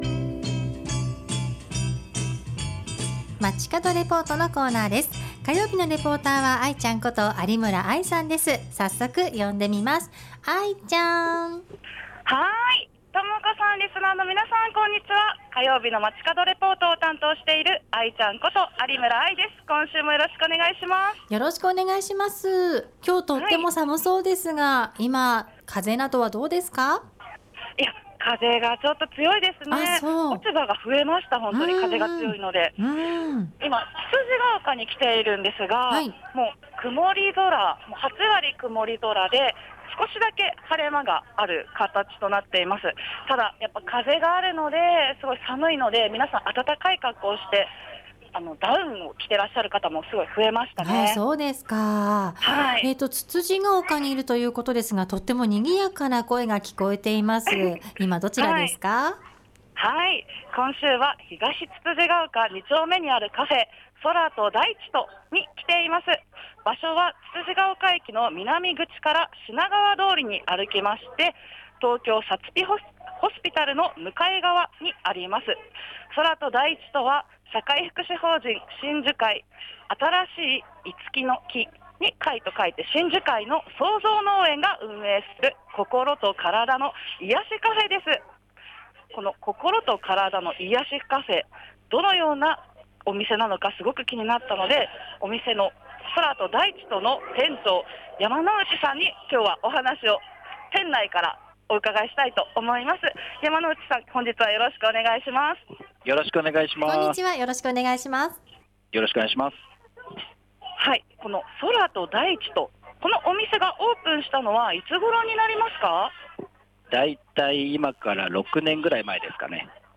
今週は東つつじヶ丘2丁目にある、ココロとカラダに優しいカフェ「空と大地と」からお届けしました。